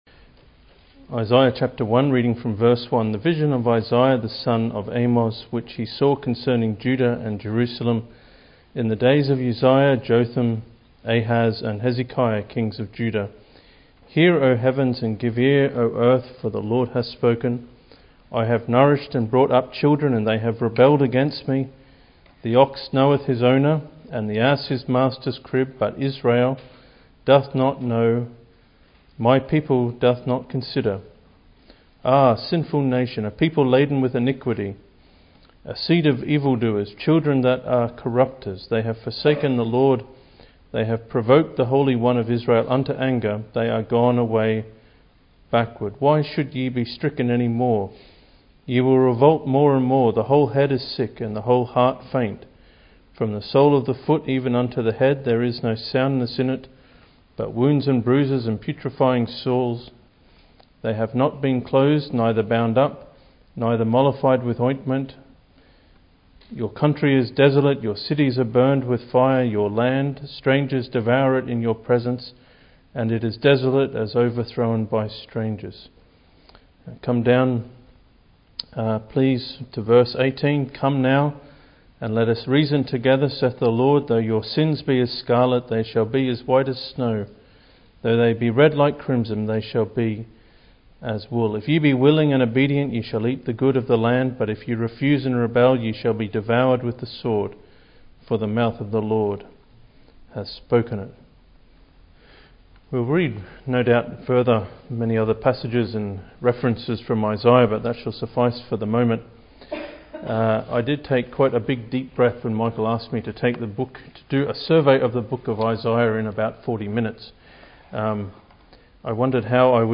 Through Him, the Lord Jesus, God will yet bring national and spiritual blessing to the nation in the Millennium (Message preached 21st Jan 2016)